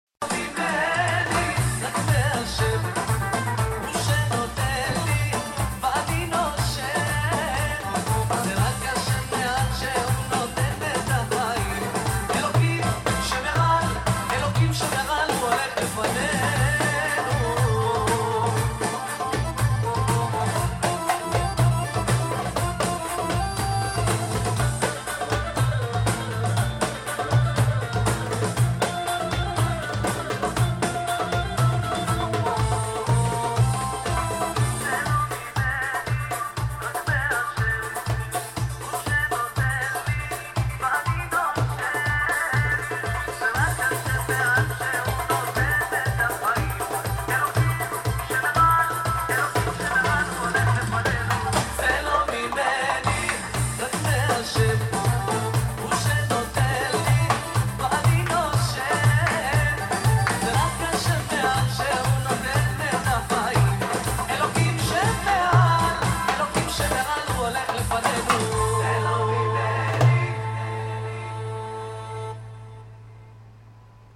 לצערי האיכות לא משהו אבל לא נורא, אפשר לשמוע גם ככה